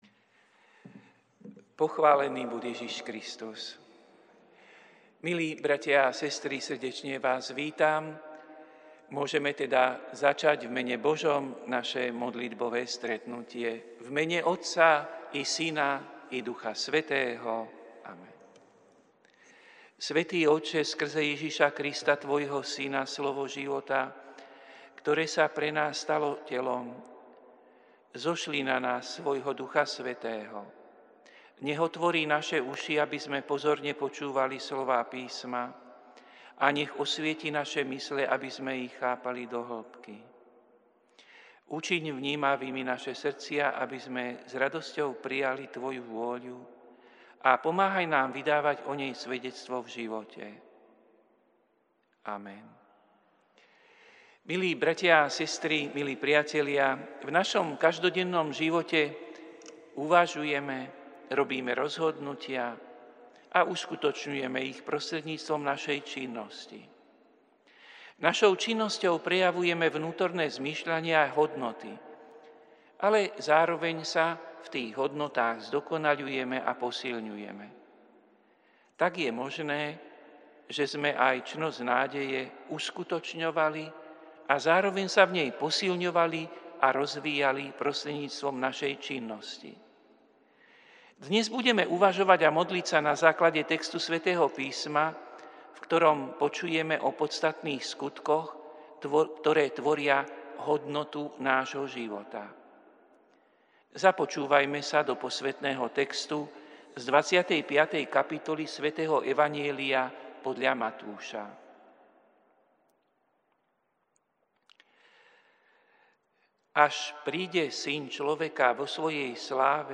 Prinášame plný text a audio záznam z Lectio divina, ktoré odznelo v Katedrále sv. Martina 8. januára 2024.